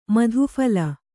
♪ mādūphala